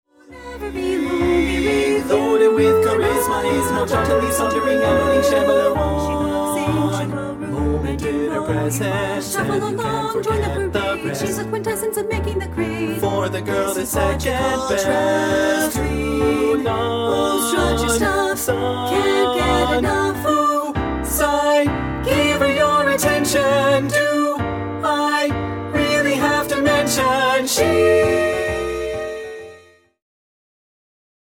• Full Mix Track